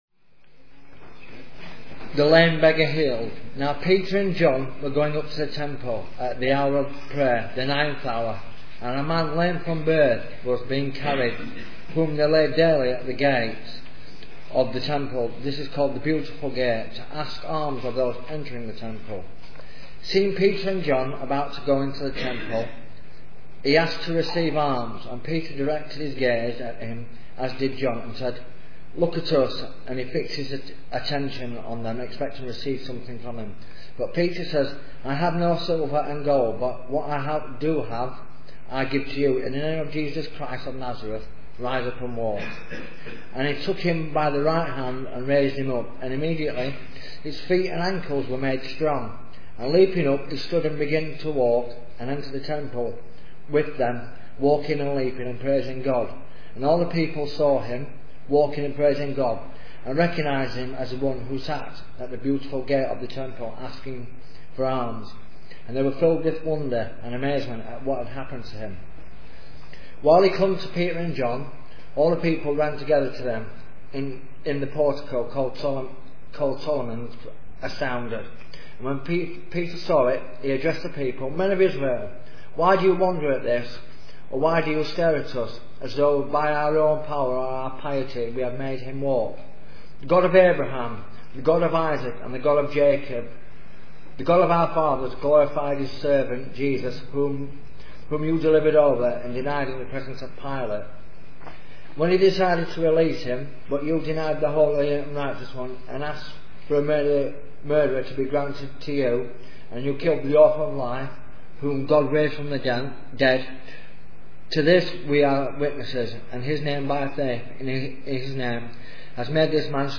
Bible Talks • Christ Church Central • Sheffield